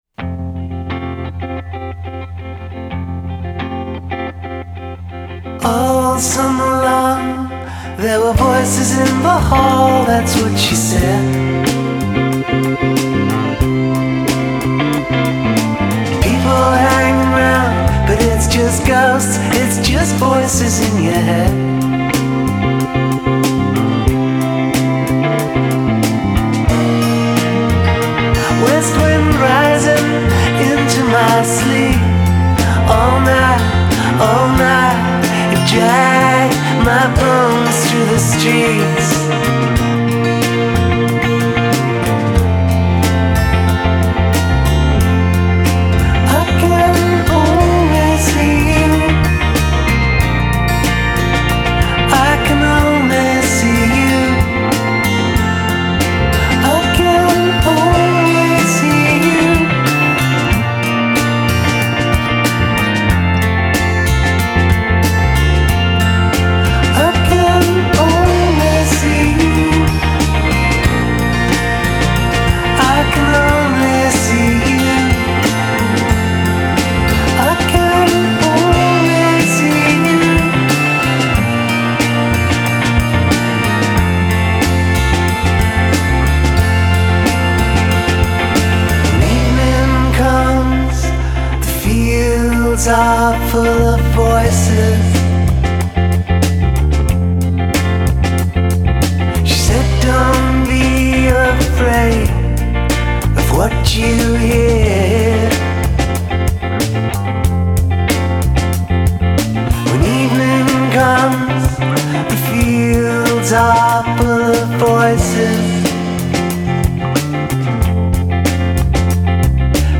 pop-folk melodioso
la voz susurrante
el disco va de canciones de amor calmas